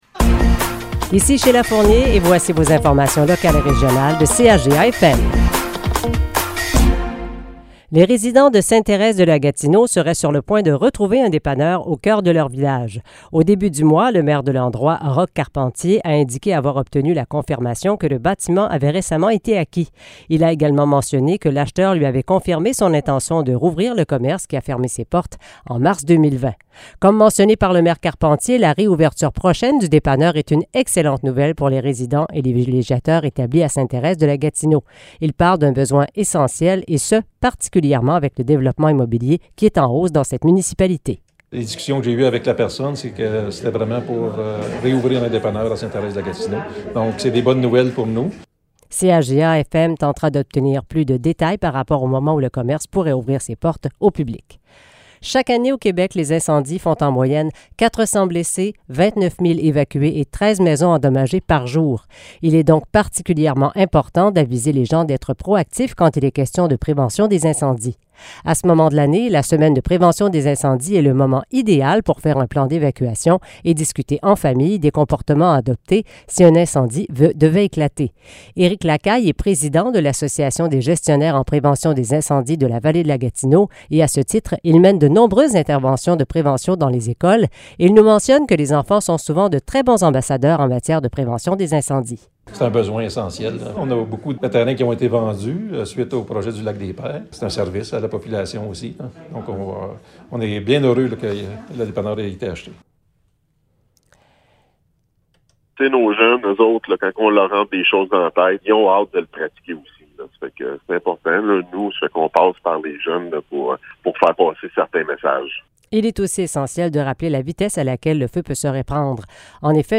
Nouvelles locales - 13 octobre 2022 - 12 h